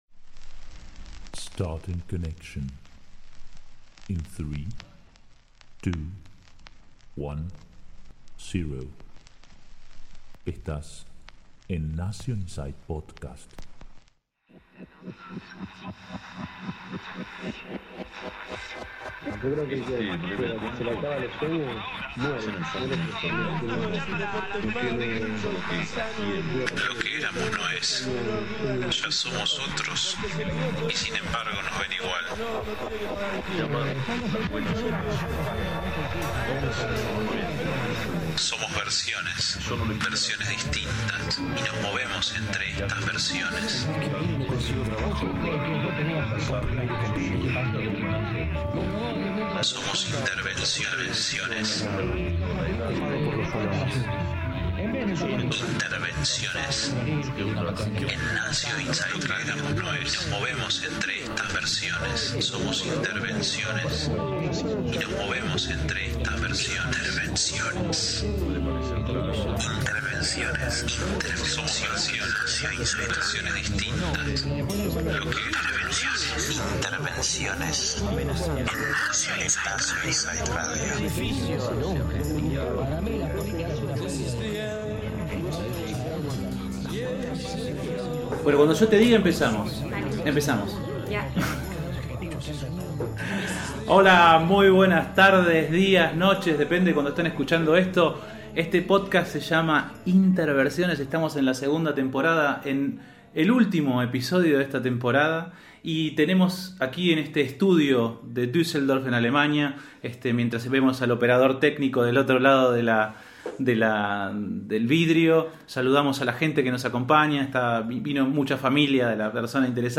Último episodio de la segunda temporada y nos despedimos charlando desde el estudio de düsseldorf